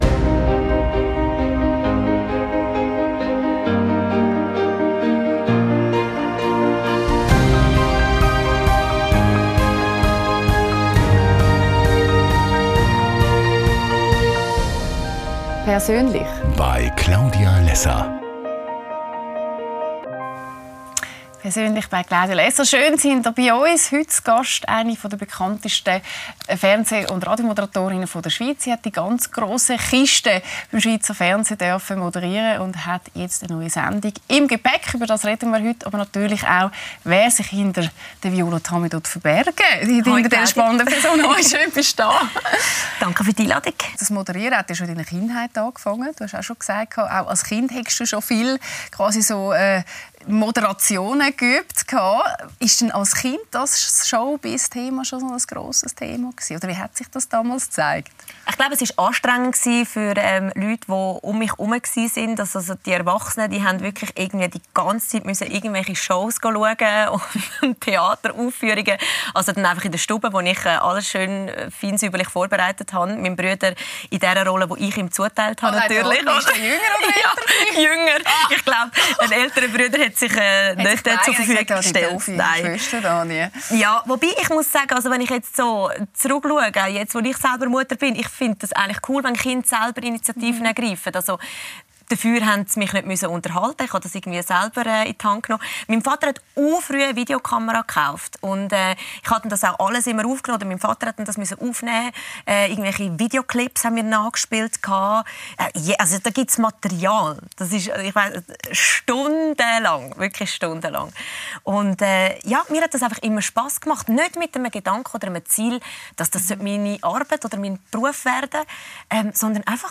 Lässer Classics – mit Viola Tami ~ LÄSSER ⎥ Die Talkshow Podcast